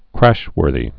(krăshwûrthē)